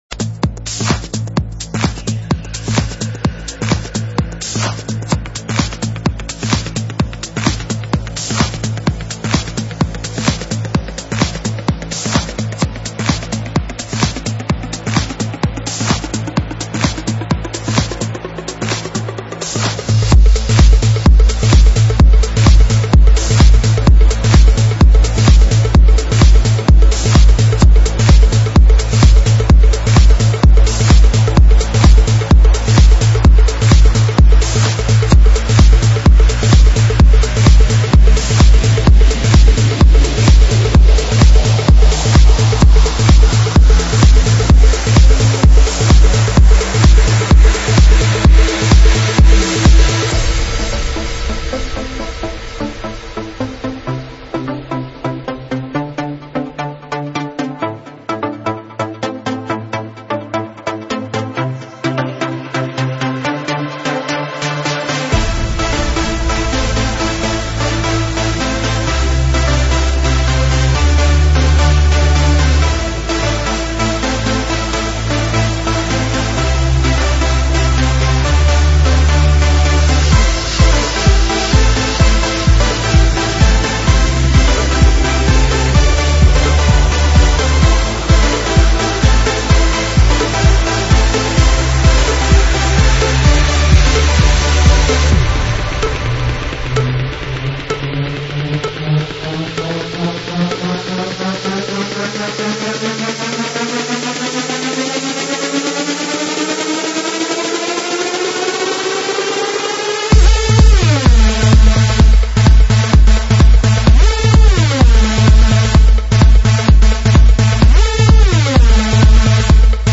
Стиль: Progressive House
Позитив | Энергия | Чувство | Ритм | Стиль | Движение